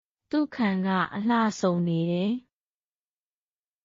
トゥ　カン　ガ　アラーソン　ニーデ
当記事で使用された音声（日本語およびミャンマー語）はGoogle翻訳　および　Microsoft Translatorから引用しております。